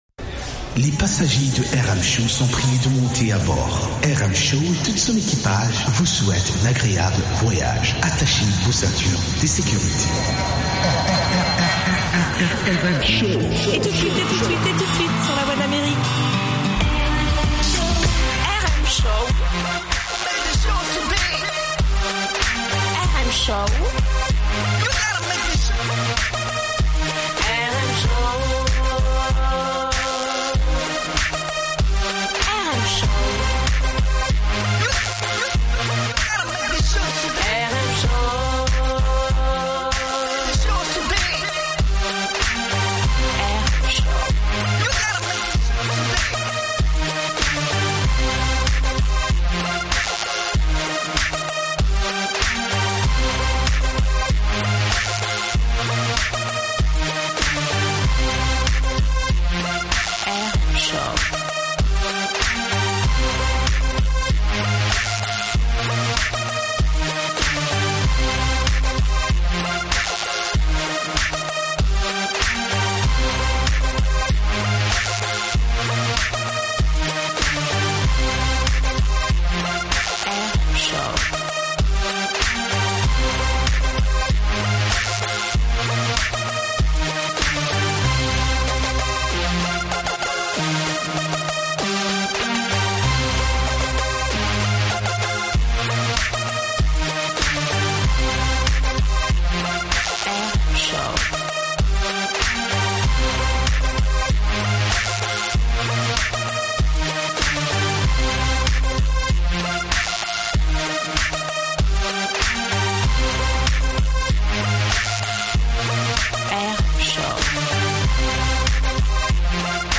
Musique internationale & comedie